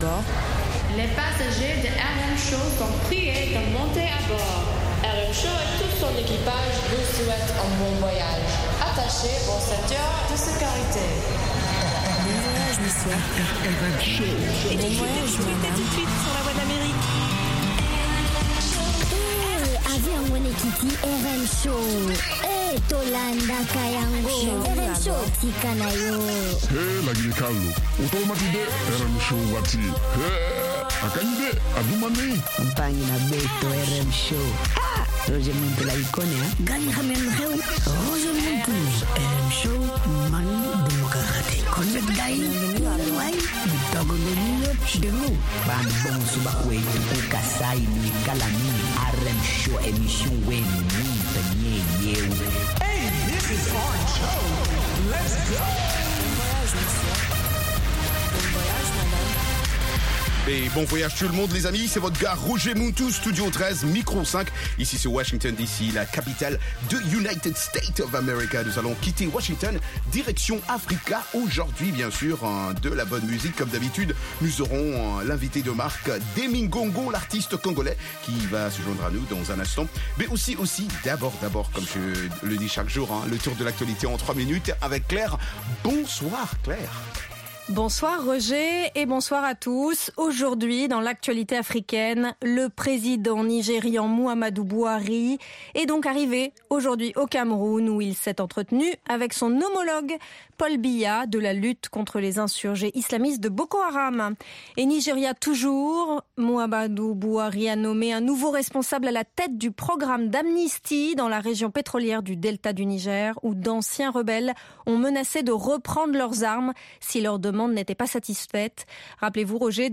Embed RM Show -Musique internationale & comedie Embed The code has been copied to your clipboard.